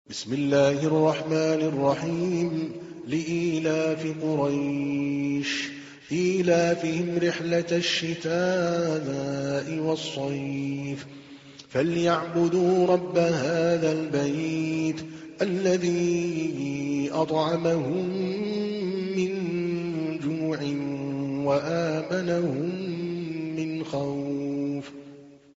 تحميل : 106. سورة قريش / القارئ عادل الكلباني / القرآن الكريم / موقع يا حسين